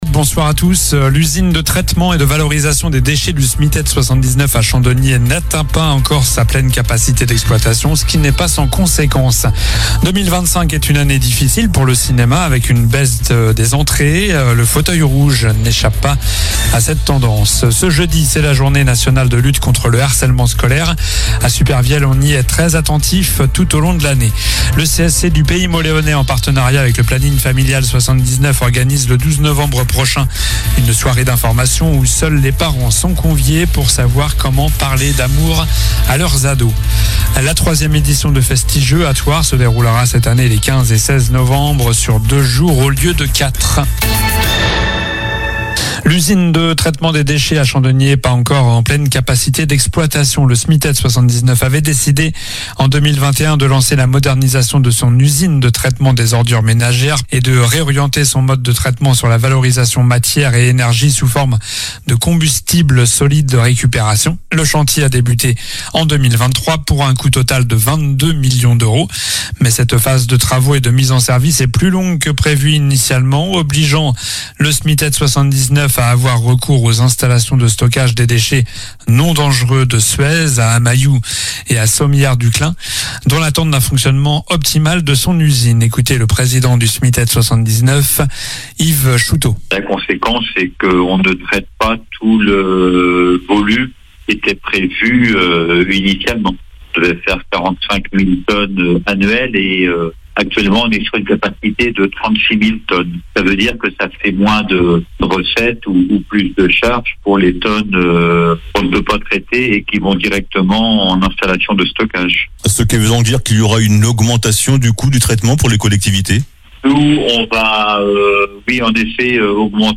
Journal du jeudi 6 novembre (soir)